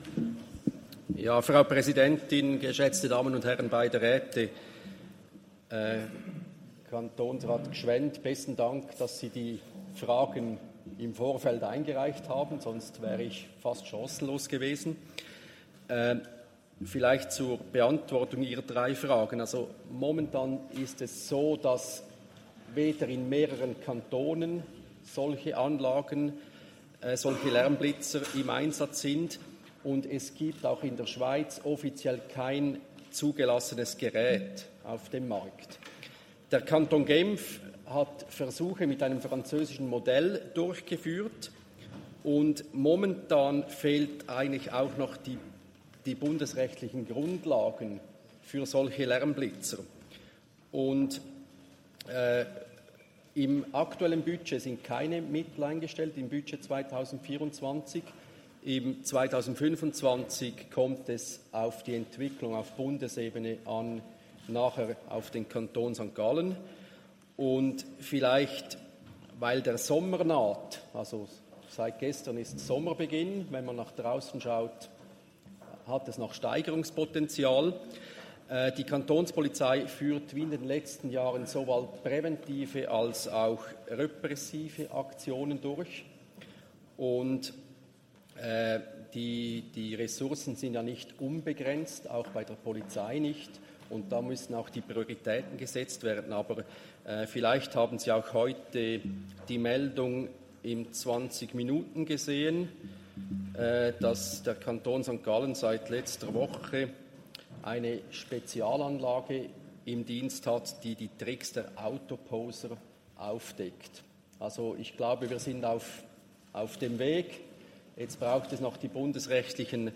Session des Kantonsrates vom 3. und 4. Juni 2024, Sommersession
3.6.2024Wortmeldung